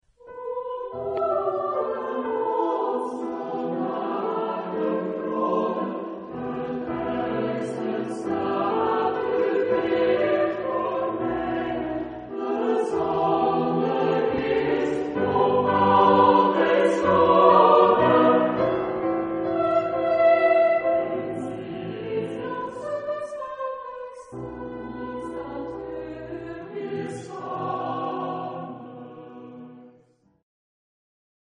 Epoque: 19th century
Genre-Style-Form: Canticle ; Sacred ; Romantic
Type of Choir: SATB  (4 mixed voices )
Instruments: Piano (1)
Tonality: E minor